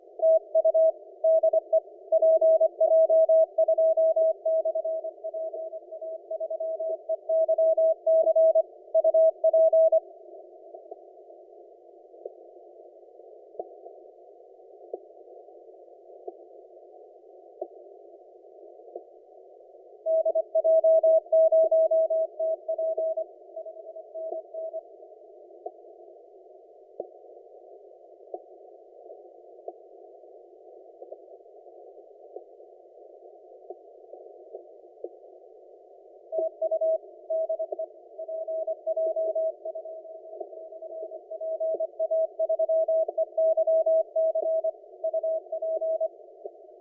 Curacao on 17m CW